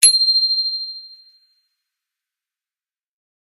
bicycle-bell_19
bell bicycle bike clang contact ding glock glockenspiel sound effect free sound royalty free Sound Effects